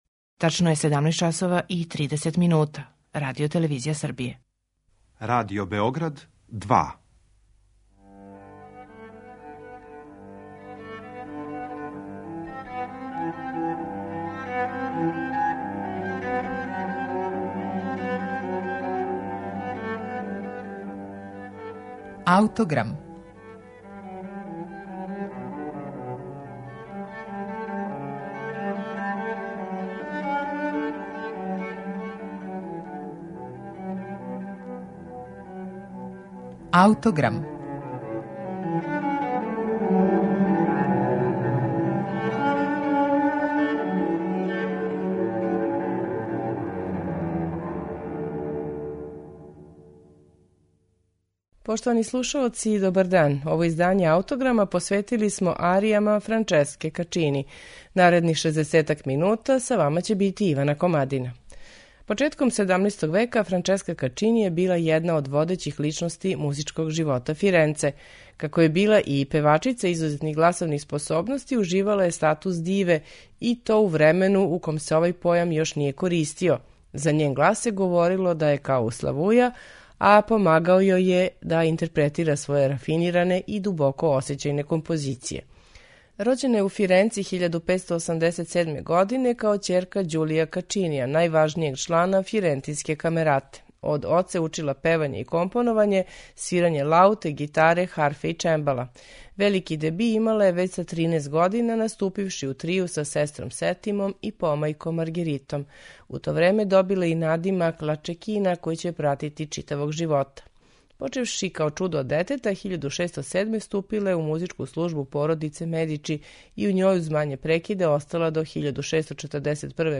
сопран
виолина
чембало
виола да гамба
лаута и удараљке
теорба, цитра и барокна гитара